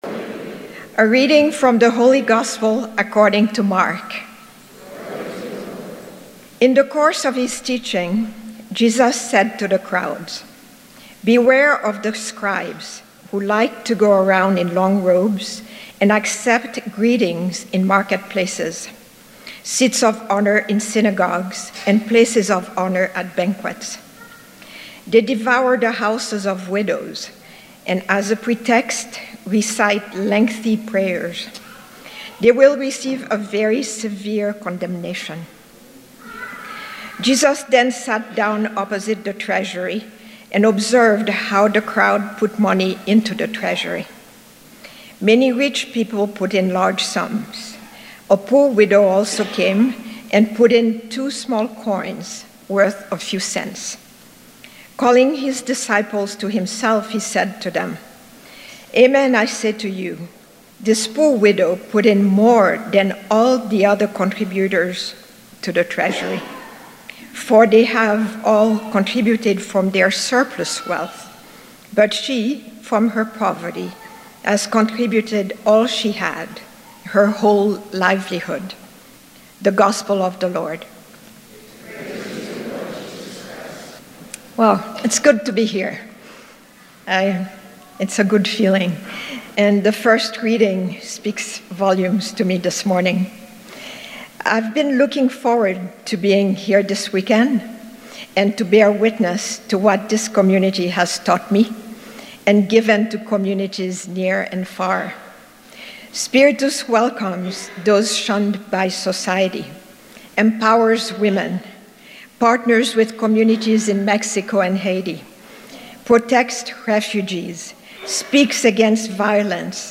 Spiritus Christi Mass November 11th, 2018